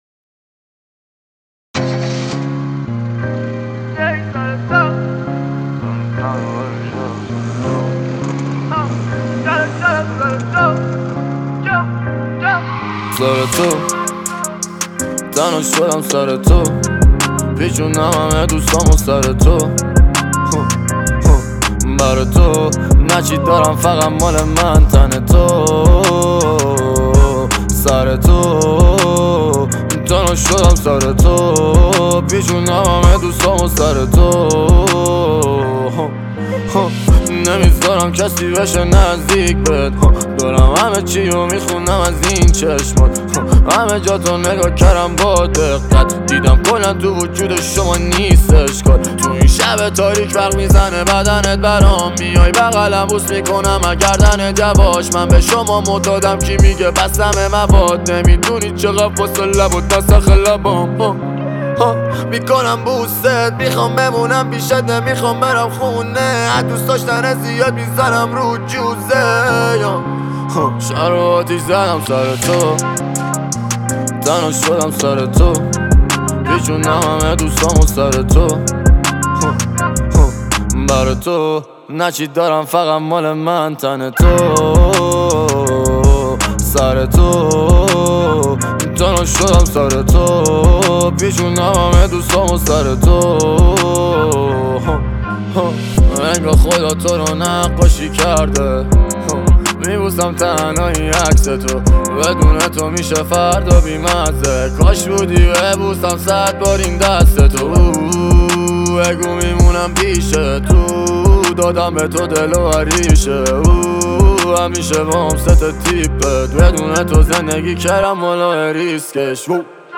موزیک غمگین